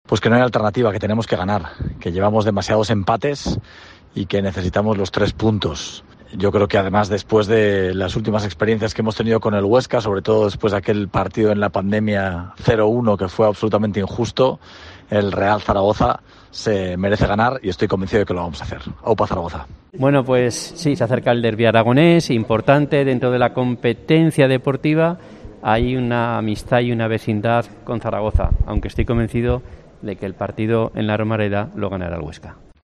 Los alcaldes de Zaragoza y Huesca, Jorge Azcón y Luis Felipe, hablan del derbi regional de este 11 de octubre.